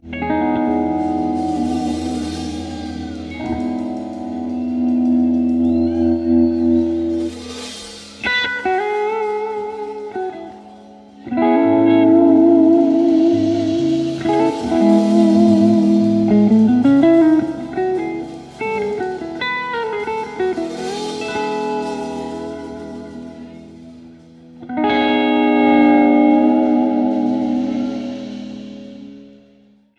Funk
Instrumental
Jamband
Jazz
R&B